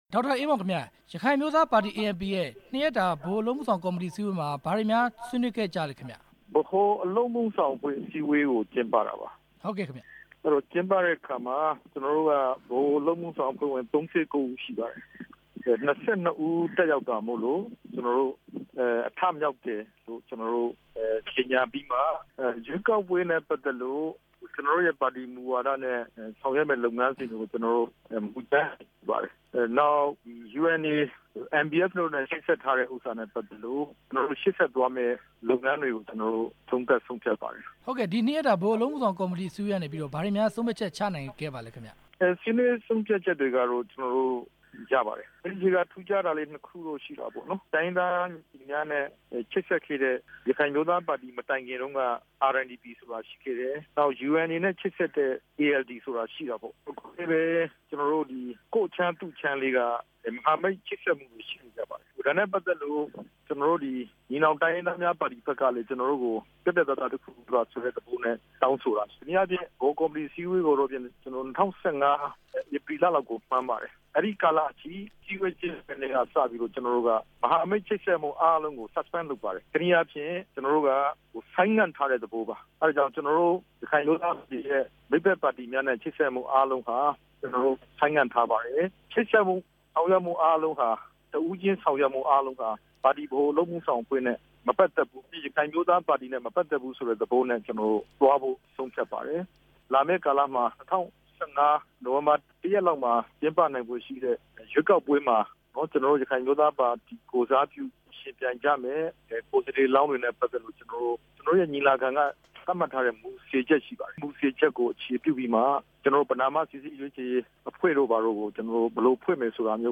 ANP ဥက္ကဋ္ဌ ဒေါက်တာအေးမောင်ကို မေးမြန်းချက်